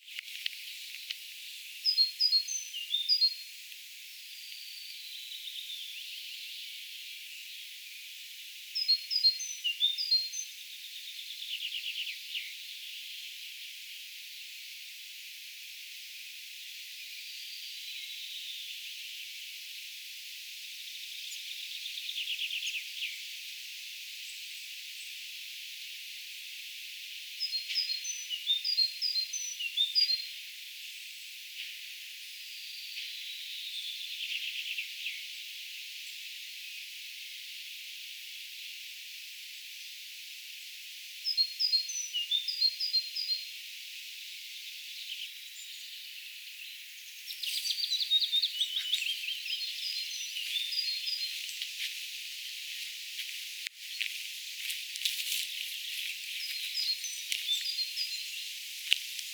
hienosti laulava talitiainen
hienosti_laulava_talitiainen.mp3